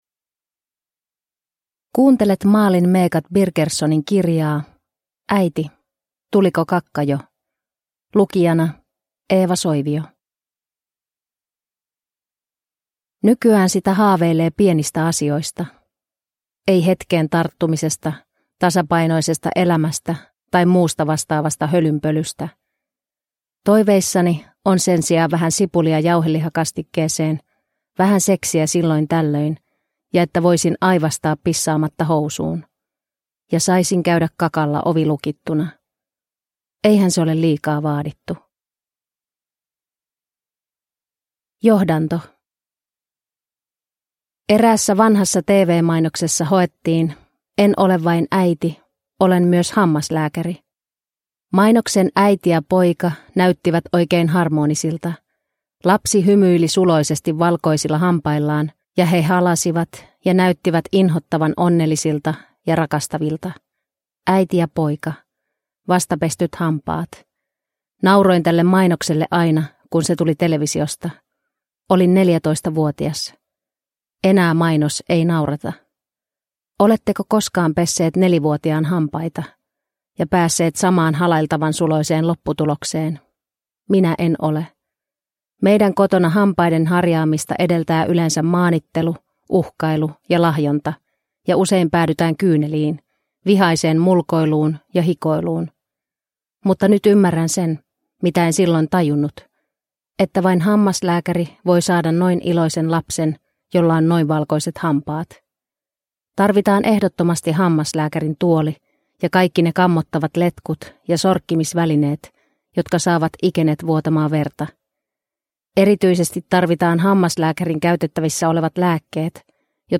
Äiti, tuliko kakka jo? – Ljudbok